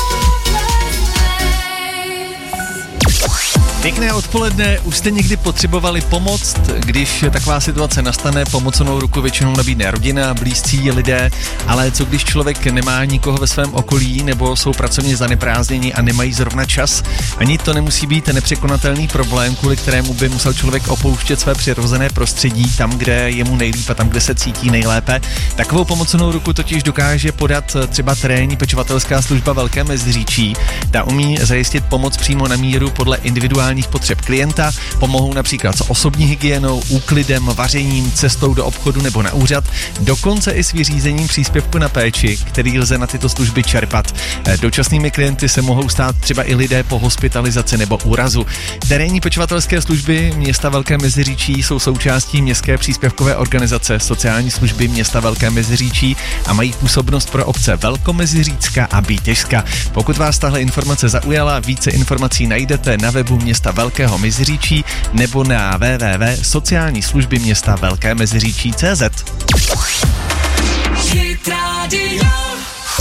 Moderátorský vstup na Hitrádiu Vysočina